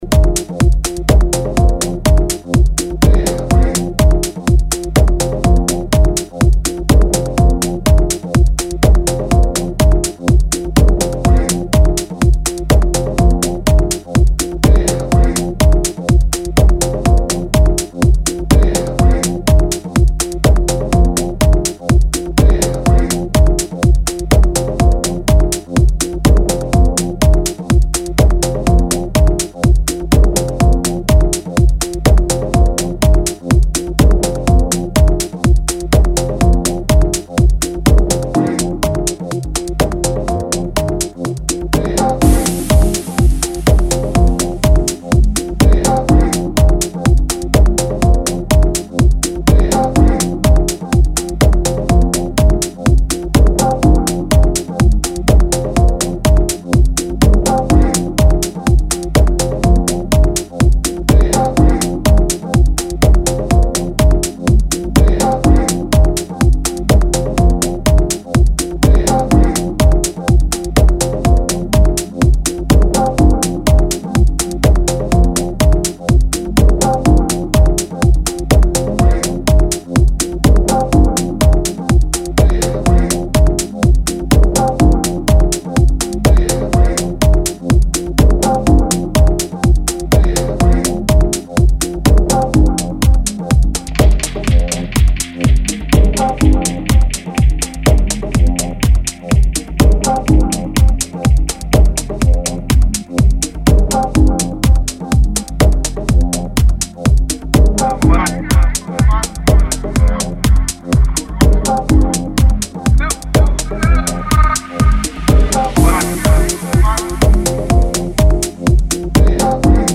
With typical piano keys and groovy bassline
a real deep-house feeling